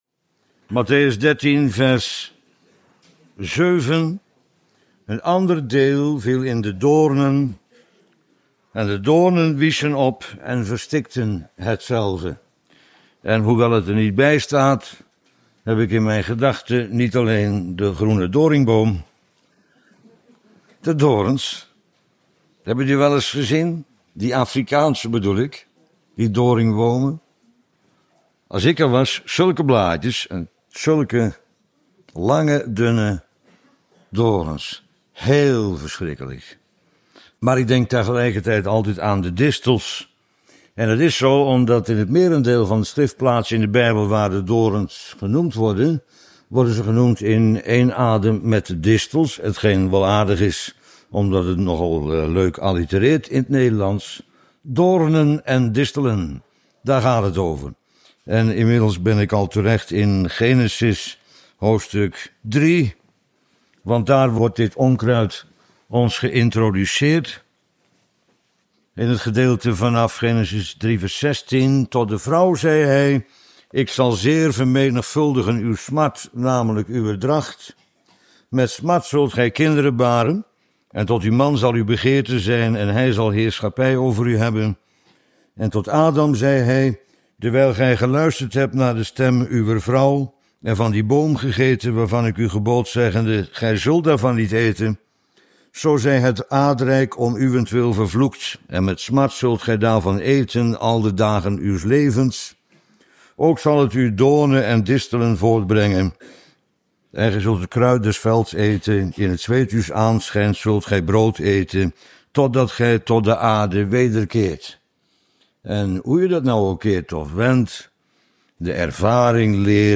Welkom bij Bijbels Panorama -Hinderende distels bijbellezing